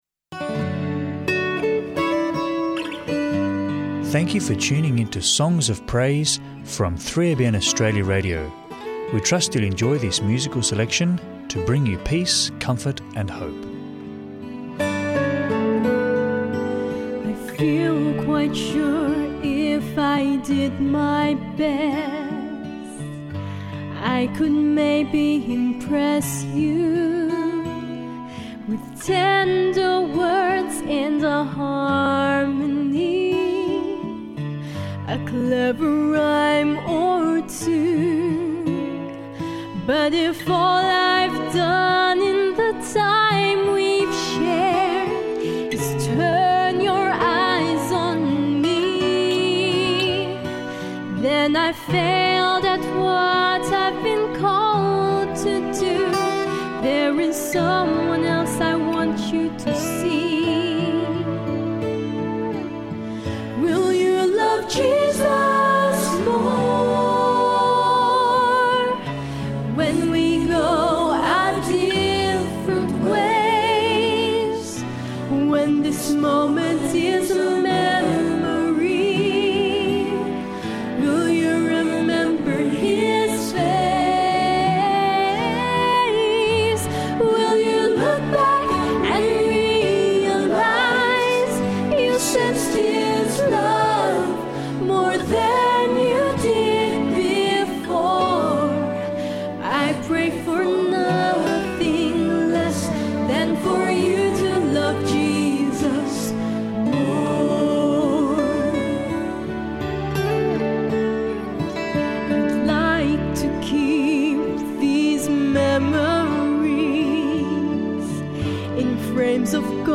Enjoy uplifting Christian hymns and worship music